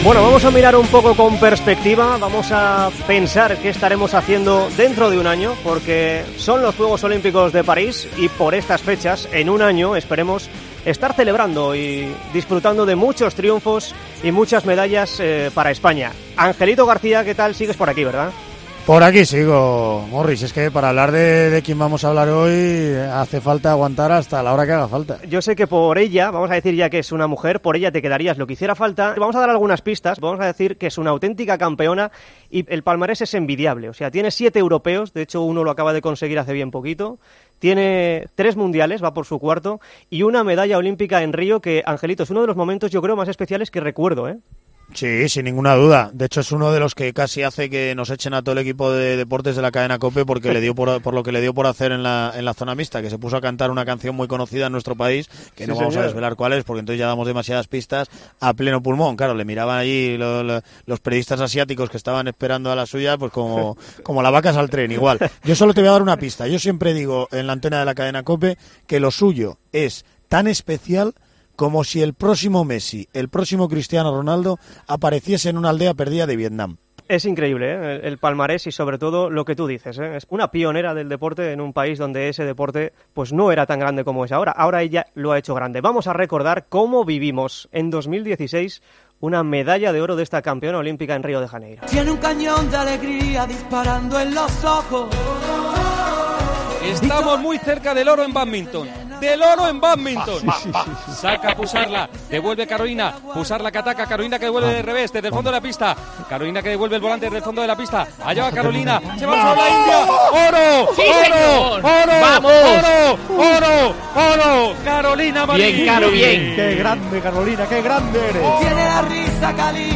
La campeona olímpica de bádminton en Río 2016, la onubense Carolina Marín , se ha pasado este sábado por los micrófonos de Tiempo de Juego para analizar su temporada con vistas a los próximos Juegos de París 2024.